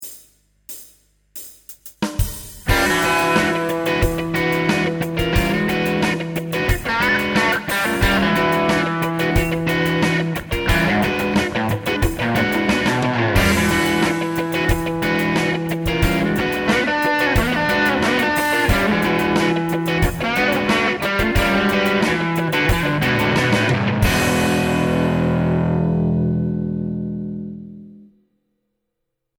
プリアンプ(LINE6 POD2)
HDR での処理は、レベル合わせのためのノーマライズと、軽いリバーブのみ。
Special Gibson P-90 Center: Neck+Bridge: Crunch
Left: Bridge: Lead
Right: Neck: Lead